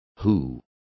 Complete with pronunciation of the translation of who.